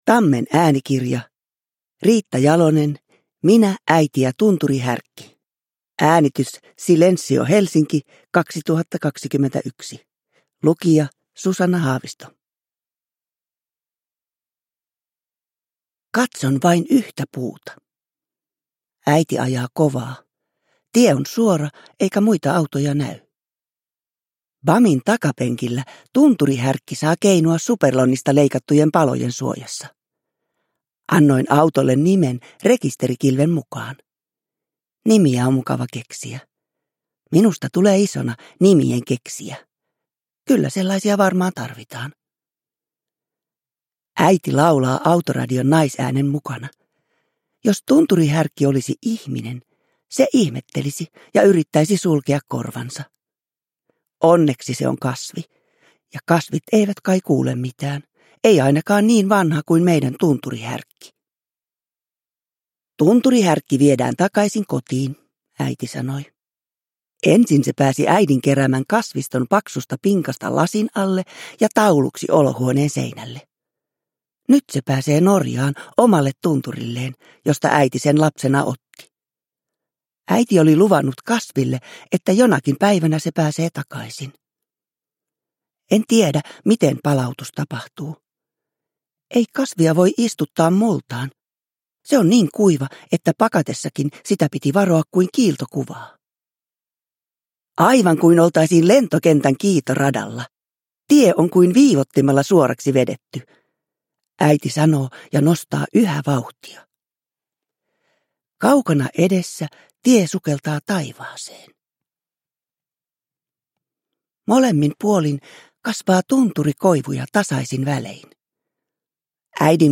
Uppläsare: Susanna Haavisto